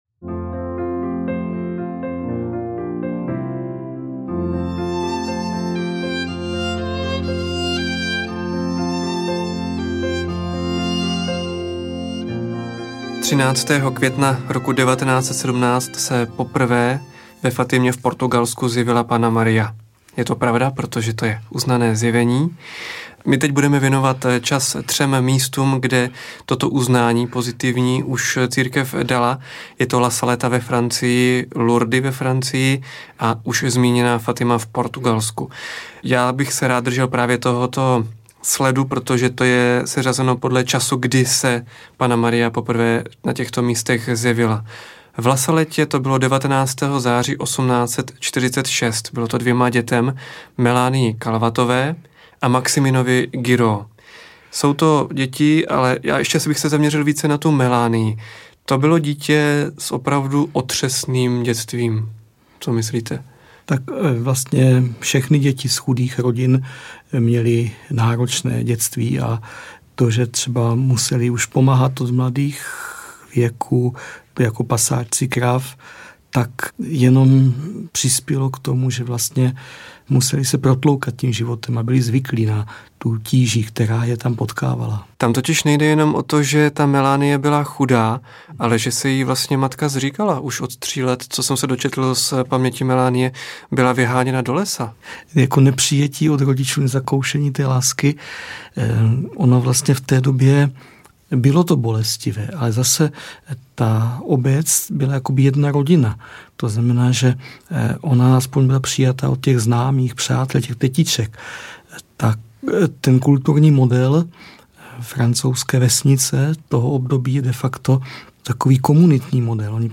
Soukromá zjevení audiokniha
Ukázka z knihy